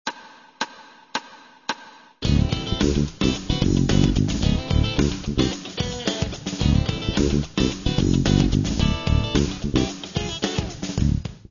По сути, это усложненный вариант риффа из предыдущего занятия с добавлением двухголосных (дабл-стопы) и одноголосных линий.
Фанковый гитарный рифф
3fast.mp3